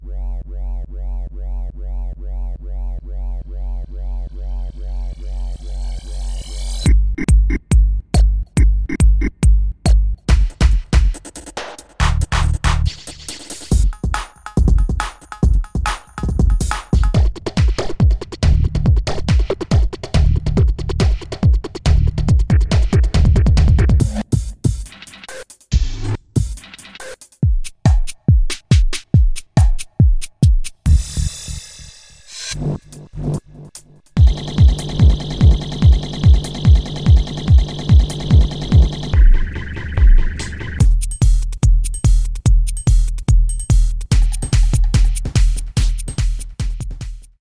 Download 556 Electronic Drum Loops
Feed your beats with the hottest electronic drum loops for RnB, Jungle, House, DnB and Techno.
Download Loops and Samples 65-180 Bpm